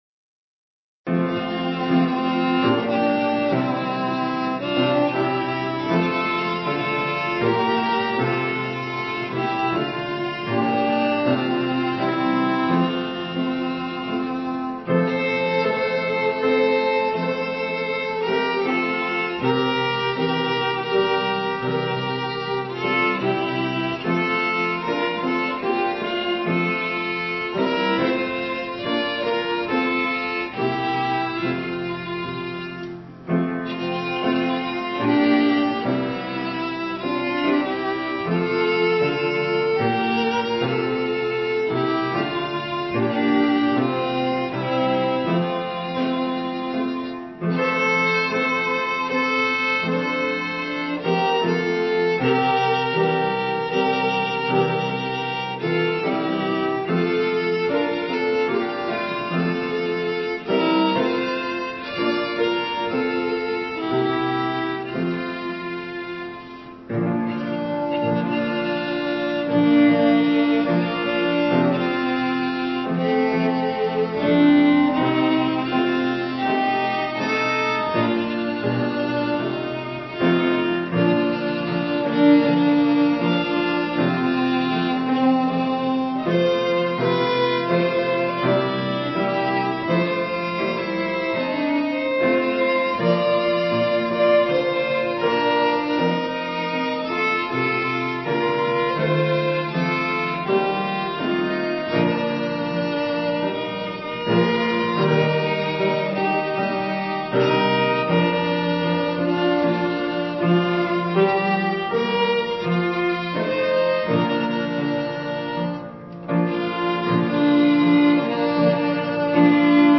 Instrumental Patriotic Prelude
violin
piano